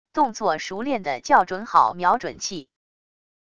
动作熟练的校准好瞄准器wav音频